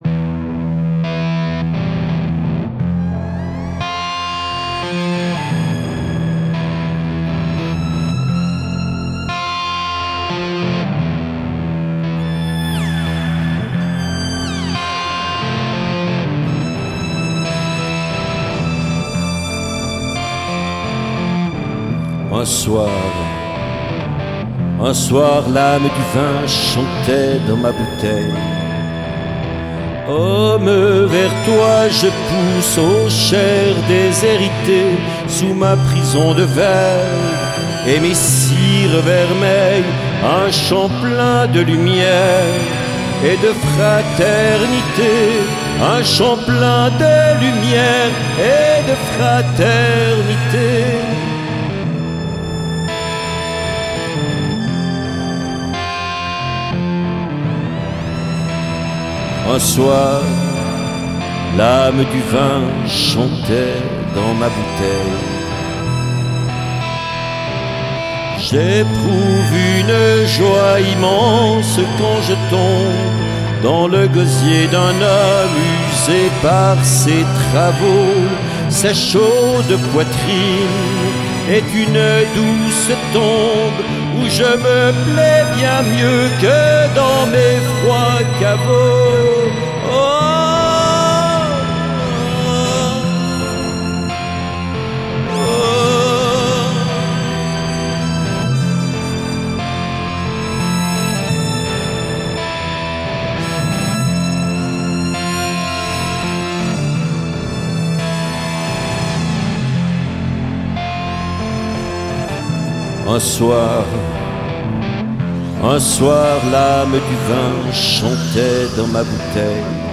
Une fusion de voix, guitare saturée et cuivre
voix & guitare électrique
trompette et électro
batterie et percussions